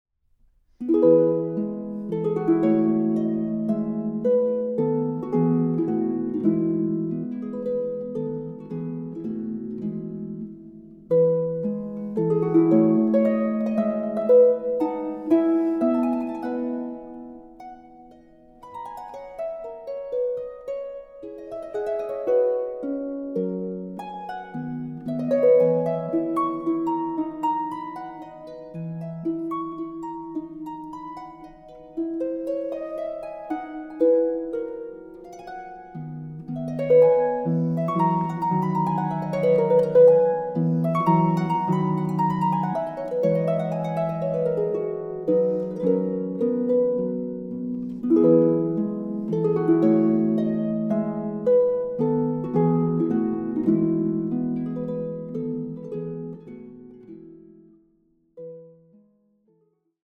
Harfe
Aufnahme: Festeburgkirche Frankfurt, 2024